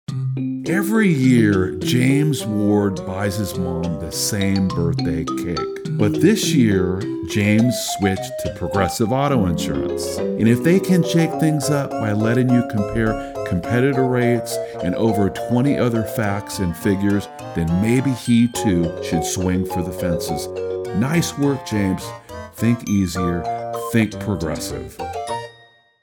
talented male voiceover artist known for his warm, rich tone that captivates listeners